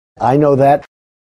На этой странице собраны аудиозаписи с голосом Дональда Трампа: знаменитые высказывания, фрагменты выступлений и публичных речей.